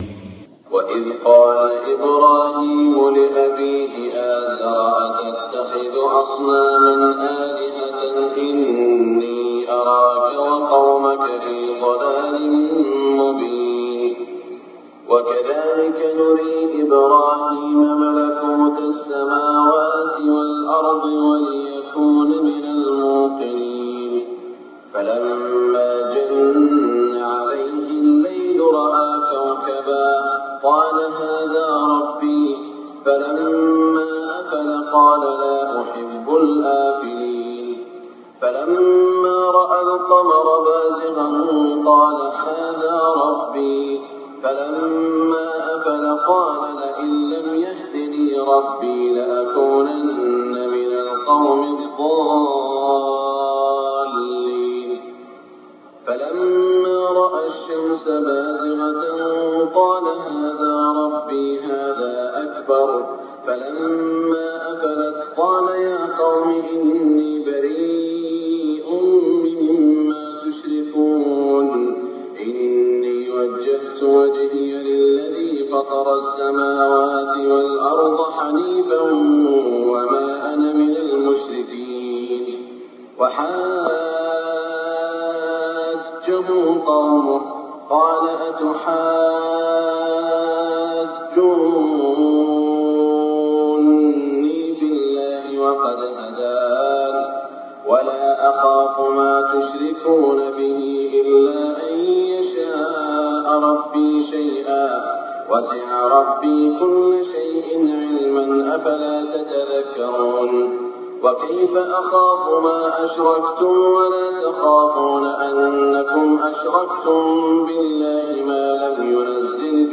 صلاة الفجر من سورة الأنعام في شهر ربيع الأول من عام ١٤٢٤هـ > 1424 🕋 > الفروض - تلاوات الحرمين